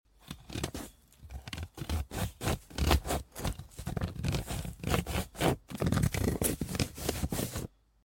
Green Pear Face Cutting 🔪🍐 Sound Effects Free Download